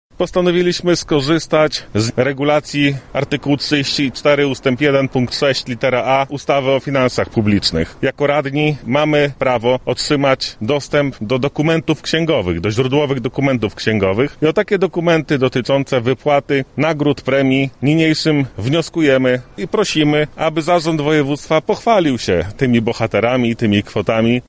• mówi radny sejmiku województwa Przemysław Litwiniuk.